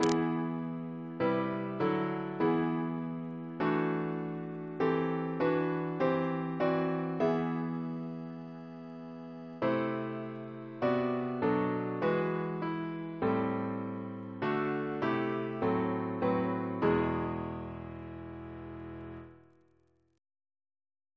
Double chant in E Composer: Chris Biemesderfer (b.1958) Note: for Psalm 149